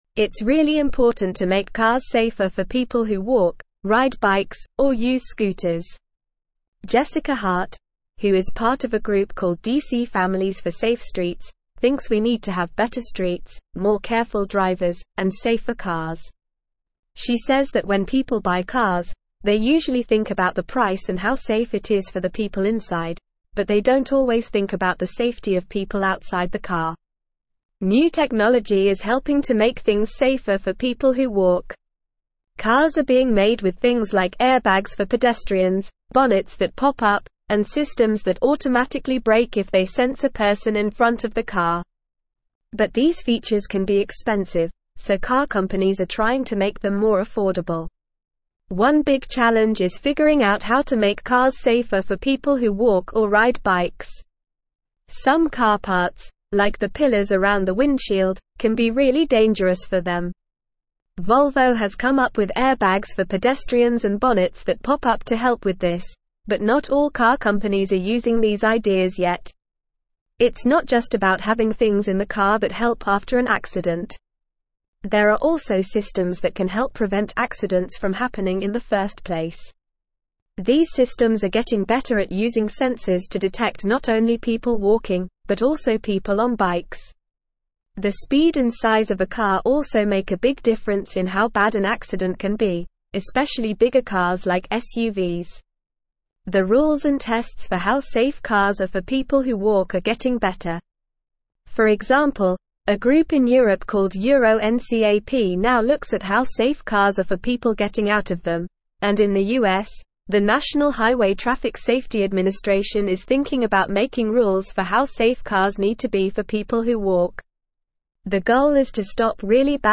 Normal
English-Newsroom-Upper-Intermediate-NORMAL-Reading-Advocating-for-Safer-Streets-Protecting-Vulnerable-Road-Users.mp3